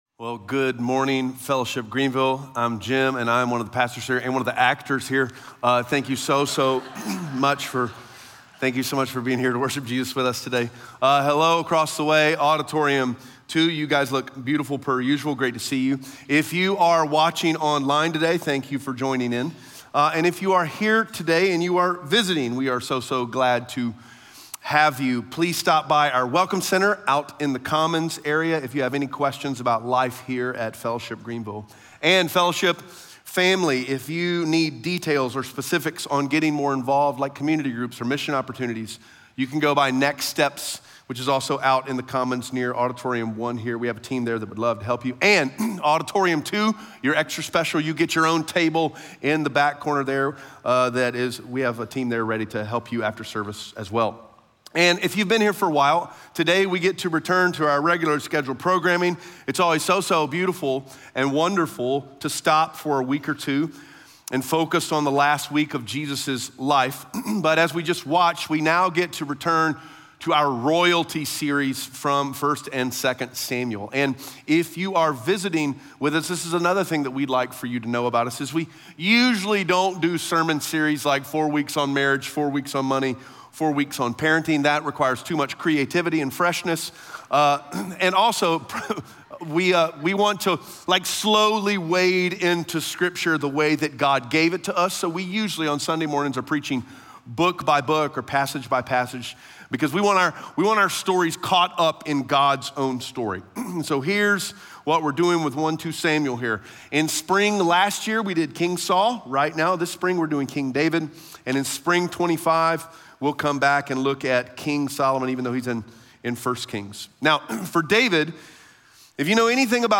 2 Samuel 20:1-22 Audio Sermon Notes (PDF) Ask a Question Scripture: 2 Samuel 20:1-22 SERMON SUMMARY If you’re a Christian, your longing for belonging to a unified team is meant to be fulfilled in the church.